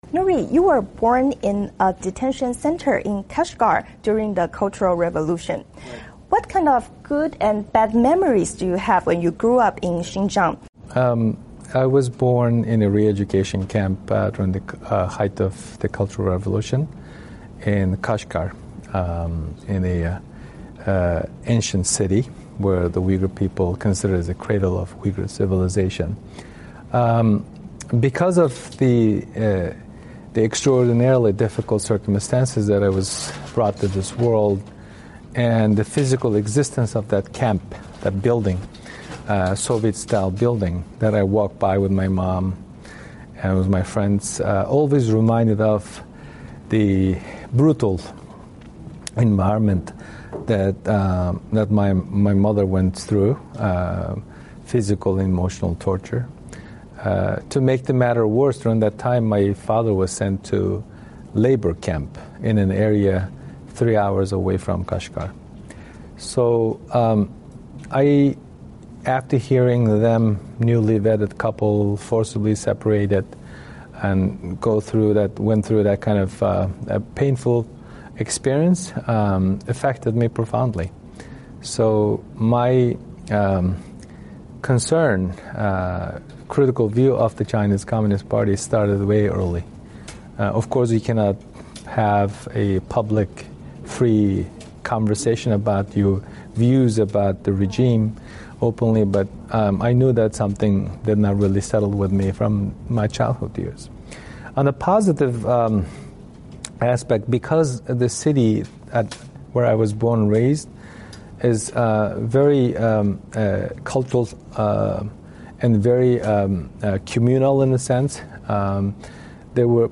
VOA专访: 专访维吾尔人权律师图凯尔